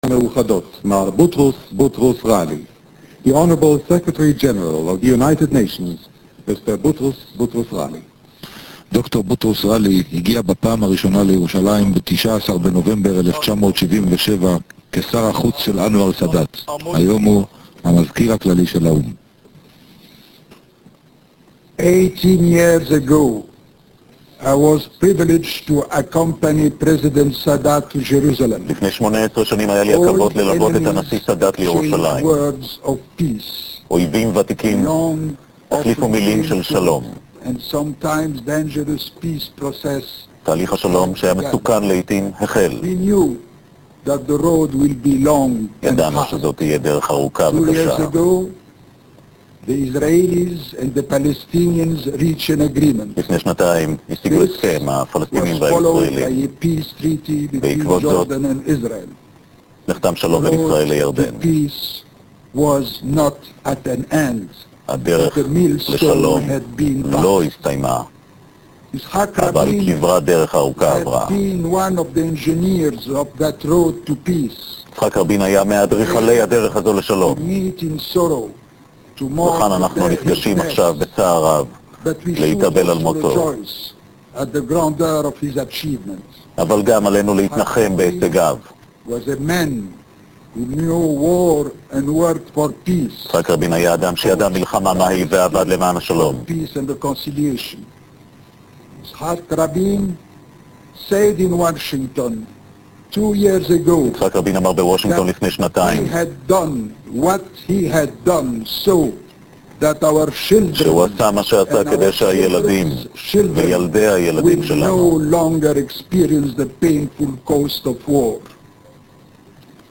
Click on the any of the icons below to download and hear speeches made during the Jerusalem Rabin memorial ceremony of November 6th 1995.
Boutros Ghali speech complete 618k